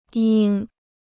鹰 (yīng): eagle; falcon.